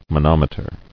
[mo·nom·e·ter]